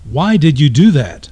In 1995, Wired magazine's AOL forum located Elwood Edwards -- whose voice recorded the sound file "You've Got Mail" -- and had him record ten additional sound files.